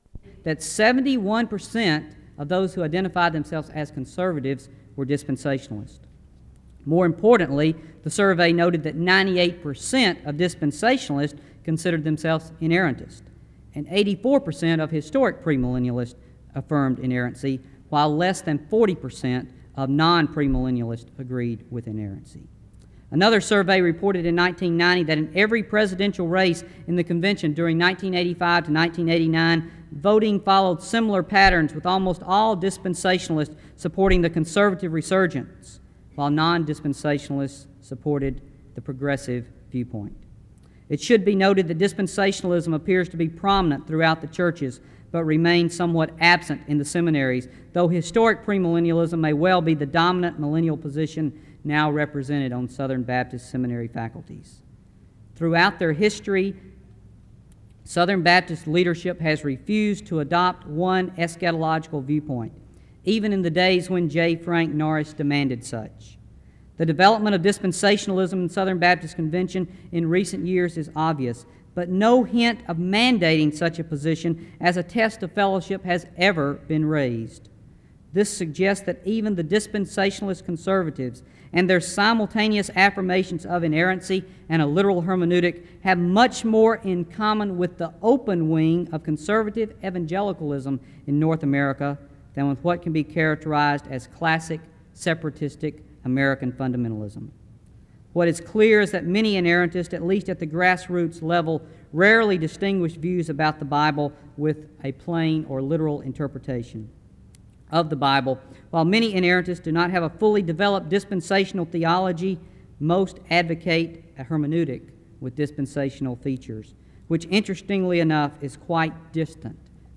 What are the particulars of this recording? • Wake Forest (N.C.)